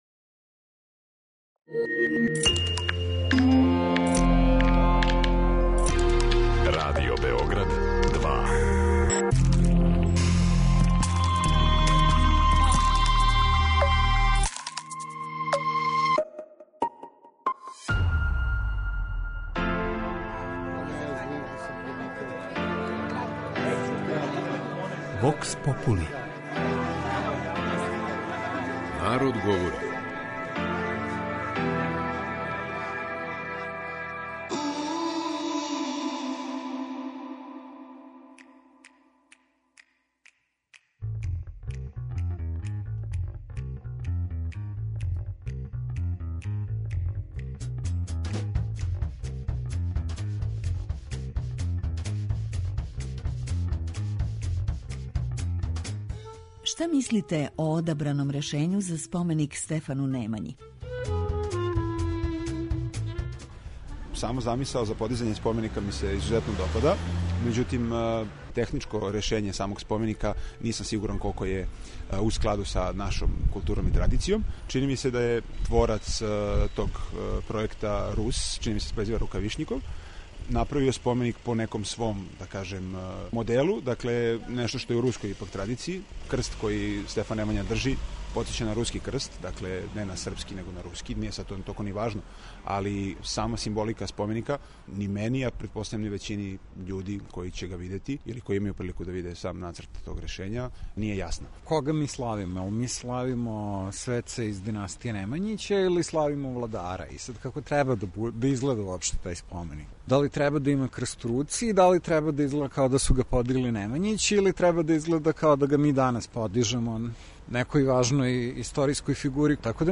Прошле недеље су објављени резултати конкурса за идејно решење такозваног Савског трга, као и споменика Стефану Немањи, који ће бити постављен у централном делу трга. Питали смо наше суграђане шта мисле о споменику и да ли им се допада овакво решење.